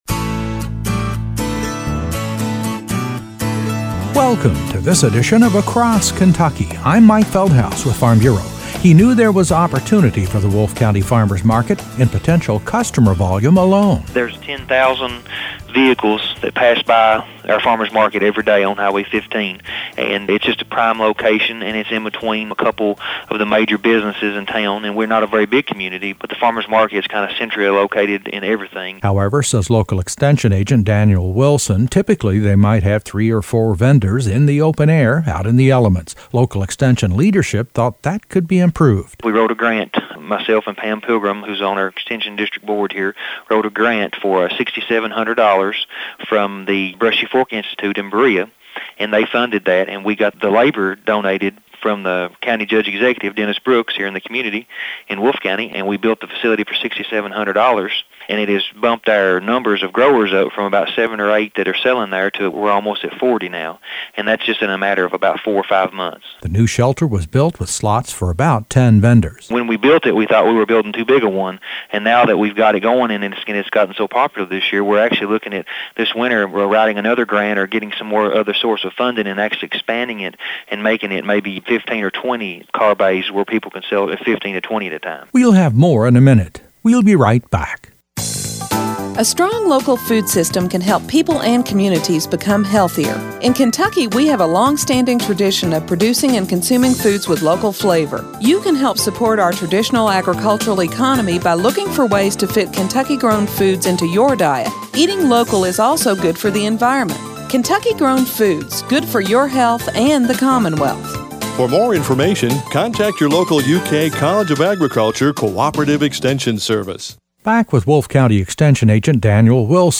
A feature report on the success of a major improvement in the farmers’ market in Wolfe County.